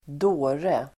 Uttal: [²d'å:re]